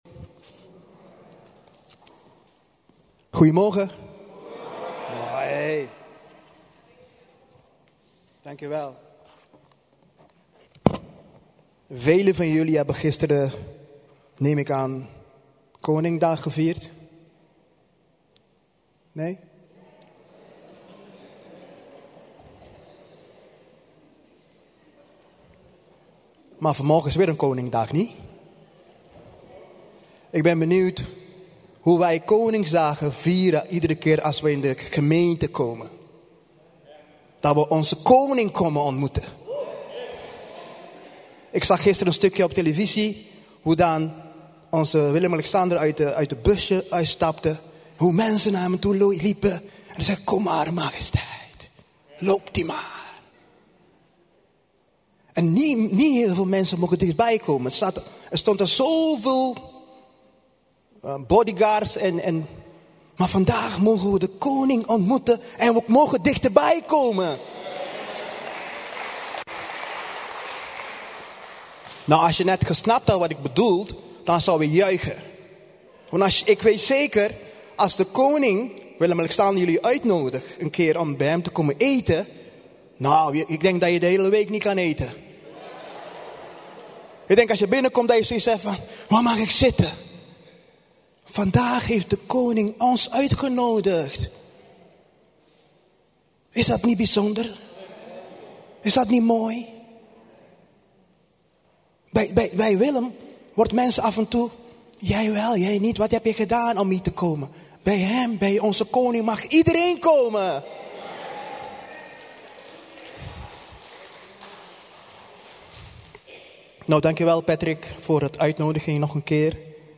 Luister hier naar de preken van de Levend Woord Gemeente Rotterdam
Heb je de dienst van zondag gemist?